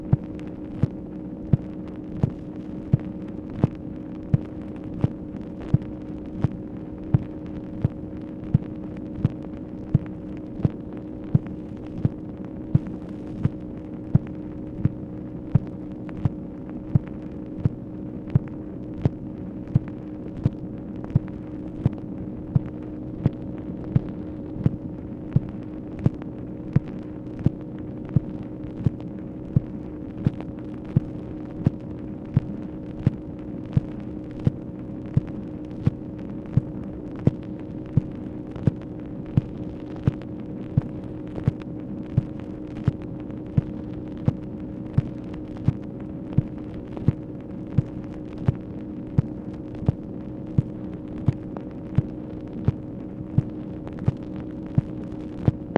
MACHINE NOISE, May 2, 1965
Secret White House Tapes | Lyndon B. Johnson Presidency